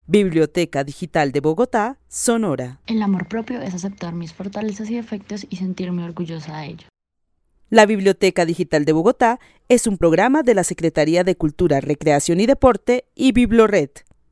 Narración oral de una mujer de la ciudad de Bogotá, que define el amor propio como la aceptación de los errores y fortalezas propias, para sentirse orgullosa de estás. El testimonio fue recolectado en el marco del laboratorio de co-creación "Postales sonoras: mujeres escuchando mujeres" de la línea Cultura Digital e Innovación de la Red Distrital de Bibliotecas Públicas de Bogotá - BibloRed.